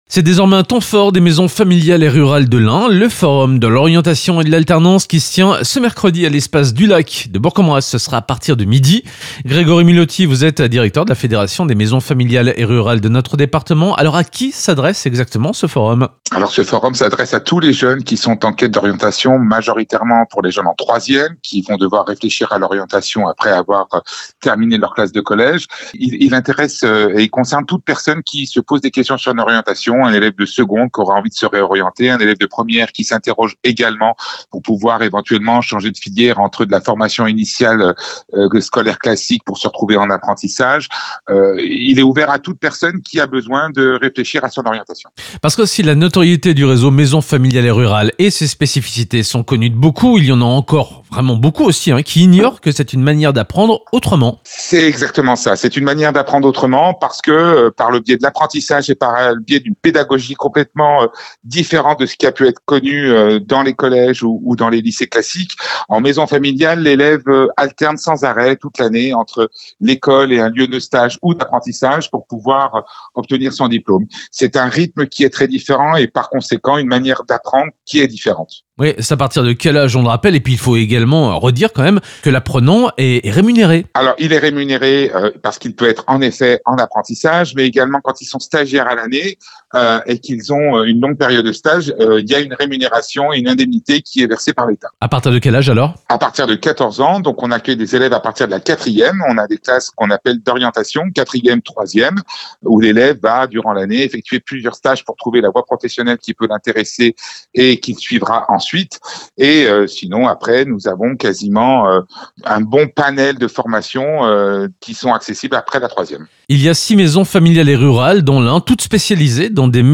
3. Interview de la Rédaction